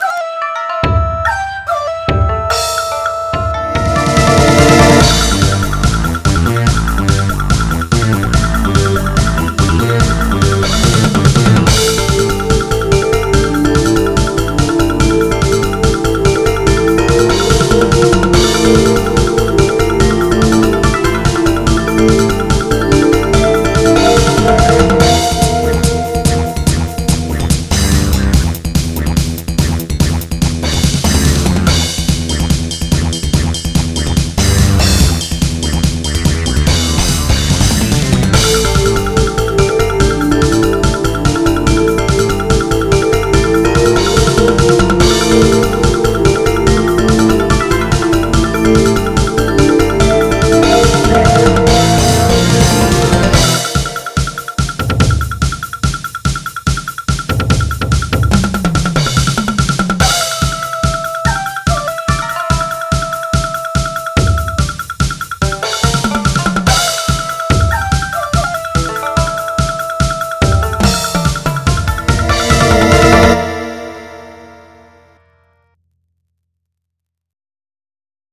Here are 3 more mp3's, extracted from midi's.